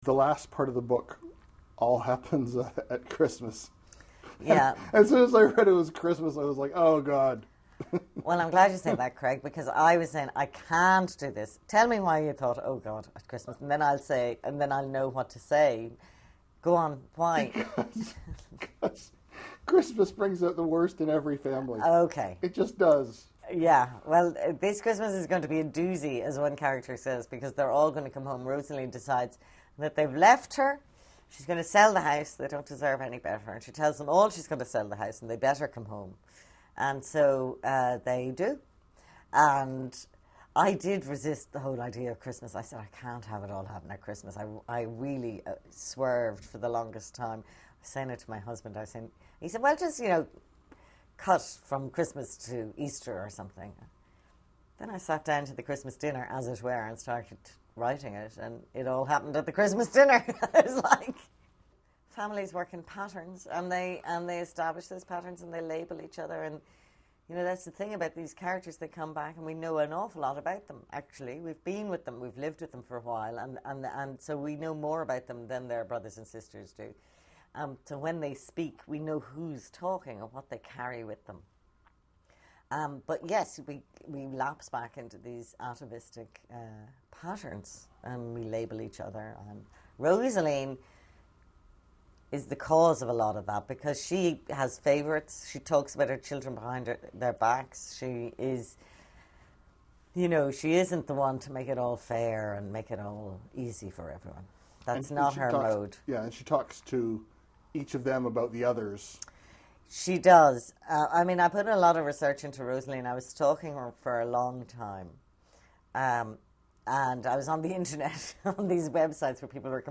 Recording Location: Toronto
Featured Speakers/Guests: Irish novelist Anne Enright
Type: Interview